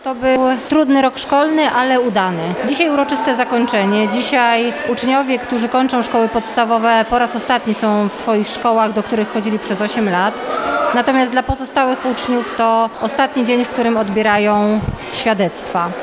O tym jaki był ten rok dla radomskiej oświaty mówi Katarzyna Kalinowska, wiceprezydent Radomia: